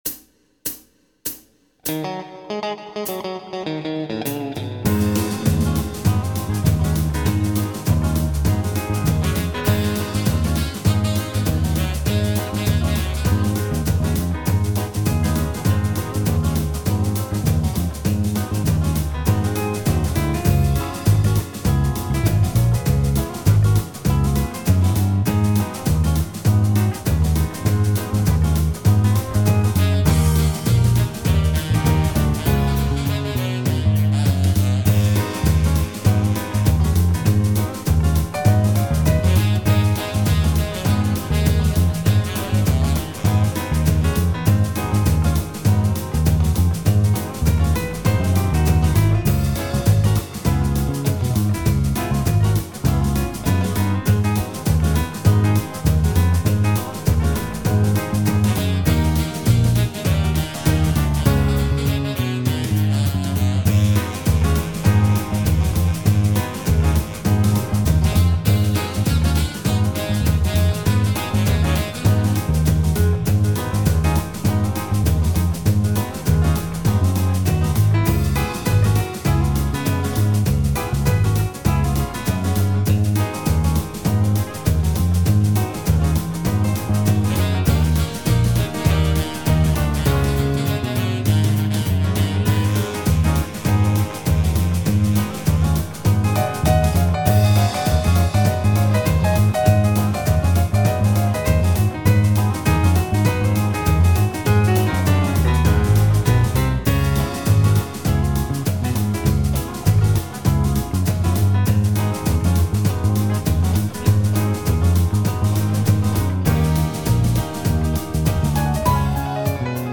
For Bass Guitar (Download).